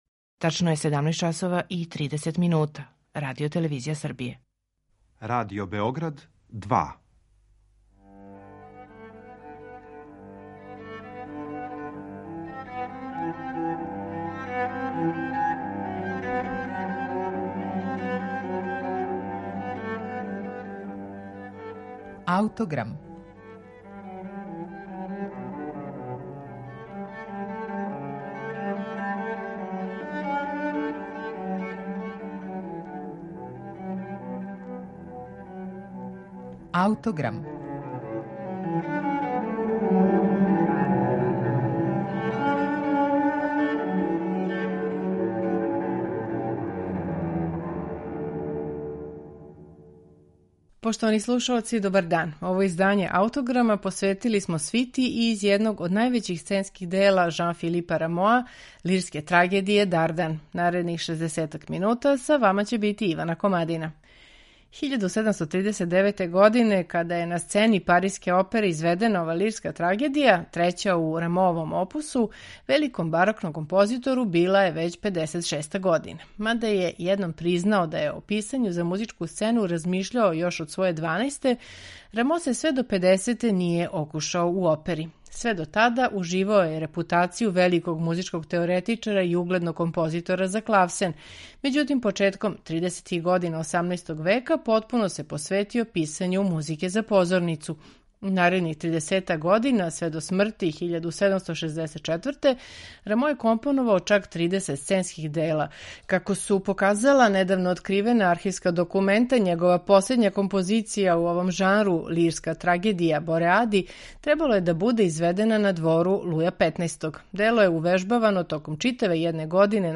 У данашњем Аутограму слушаћемо свиту за лирску трагедију „Дардан" Жан-Филипа Рамоа, рађену по првобитној верзији из 1739. године. Чућете је на концертном снимку који је на оригиналним барокним инструментима остварио „Оркестар 18. века", под управом Франса Бригена.